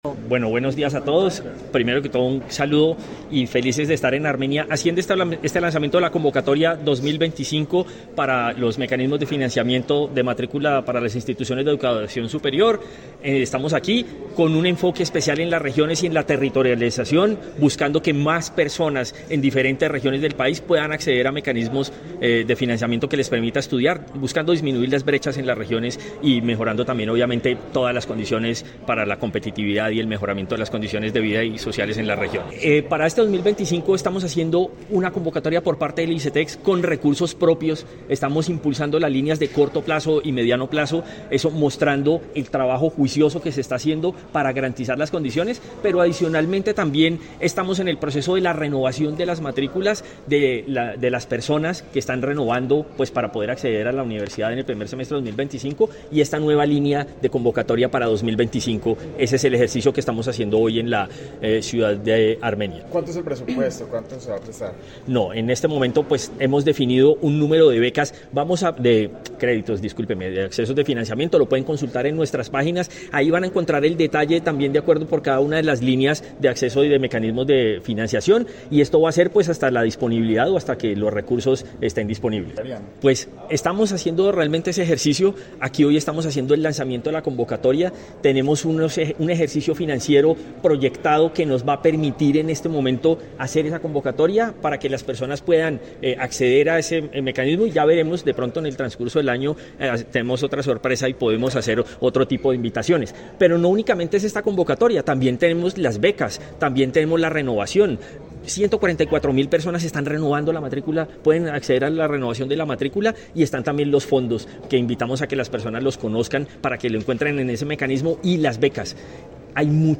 Álvaro Urquijo, Presidente Icetex
El presidente de ICETEX, Álvaro Urquijo Gómez, durante la presentación de la convocatoria en la ciudad de Armenia, Quindío, expresó “Esta convocatoria es también una invitación a los jóvenes colombianos para que tengan el apoyo del ICETEX que impulsará sus estudios por primera vez.